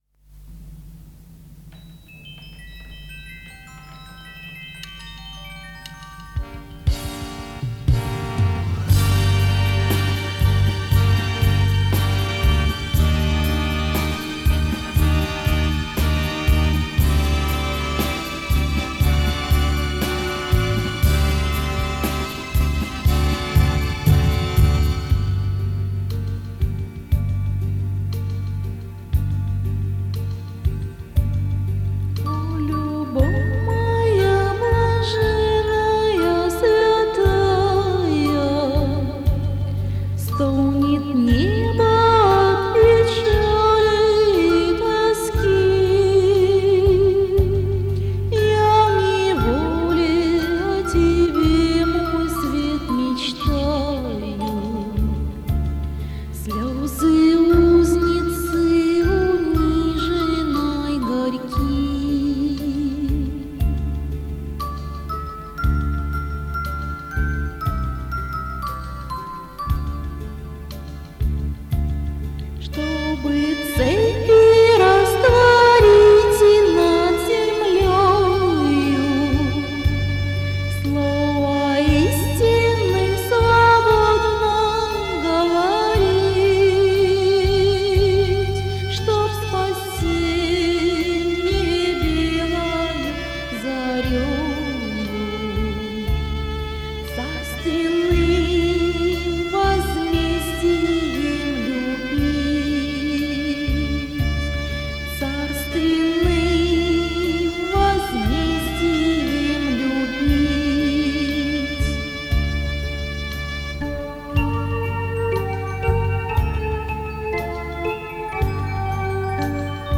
Мистическая музыка Духовная музыка